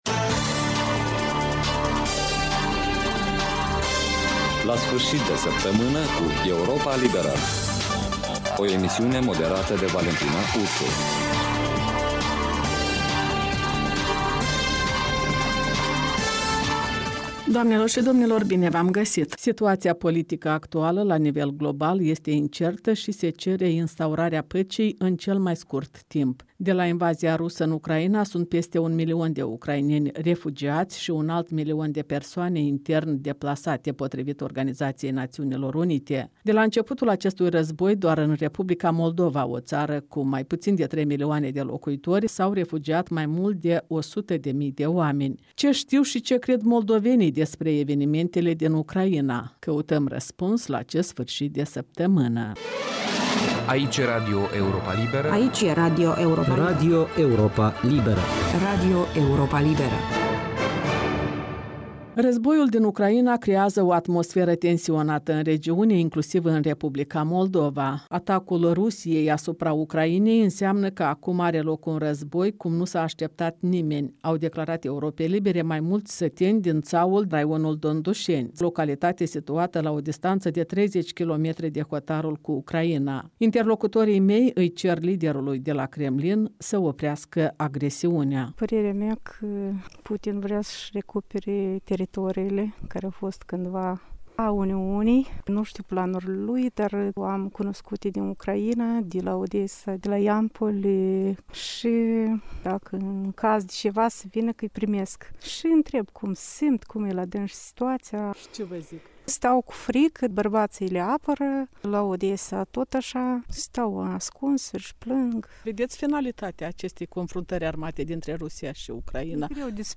de vorbă cu locuitorii de la hotarul moldo - ucrainean